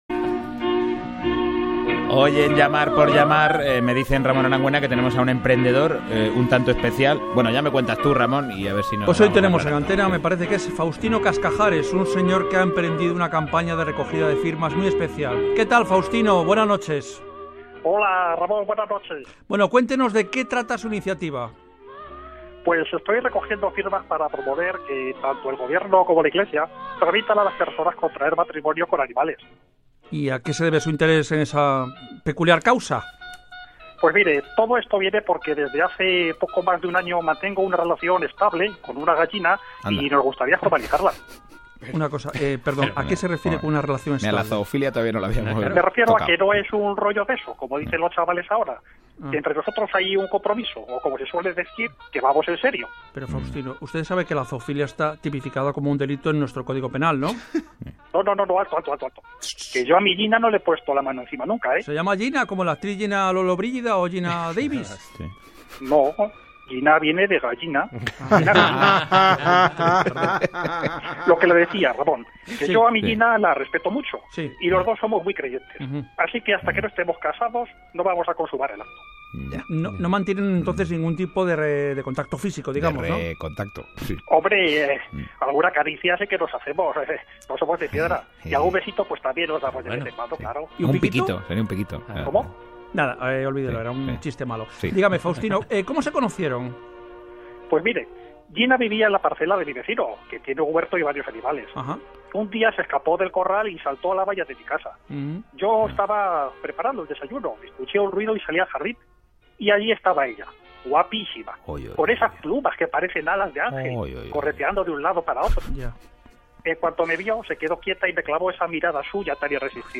El periodista Ramón Arangüena entrevista en el programa Gente despierta (Radio Nacional de España) a un curioso personaje con una historia completamente surrealista a su espalda… solo hay que dar al play:
entrevistahombre-gallina-rne.mp3